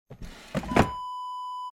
Dresser Drawer Close Wav Sound Effect #1
Description: The sound of a wooden dresser drawer being closed
A beep sound is embedded in the audio preview file but it is not present in the high resolution downloadable wav file.
Keywords: wooden, dresser, drawer, push, pushing, close, closing
drawer-dresser-close-preview-1.mp3